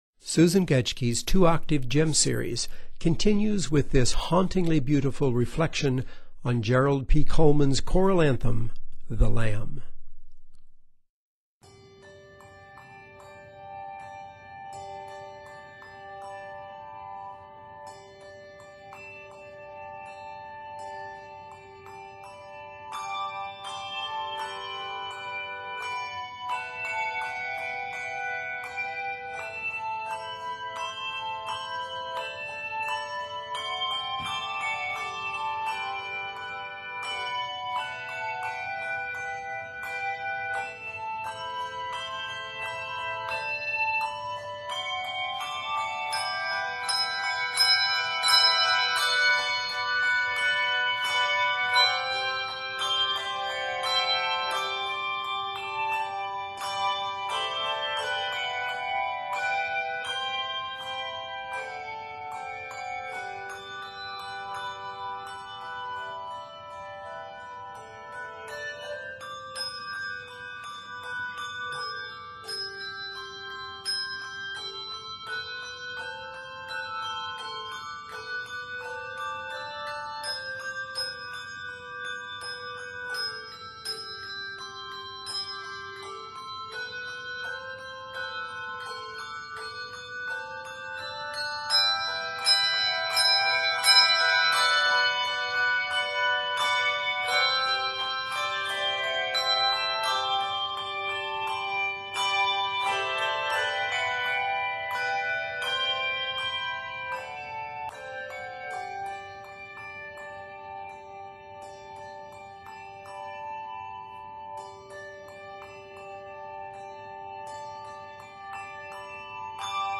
hauntingly beautiful reflection
Arranged in G Major